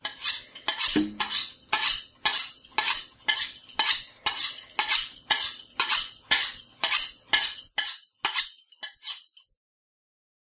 锐化1
描述：磨菜刀的声音。
Tag: 厨房 录制 变焦 44kHz的 刀片 H1 立体声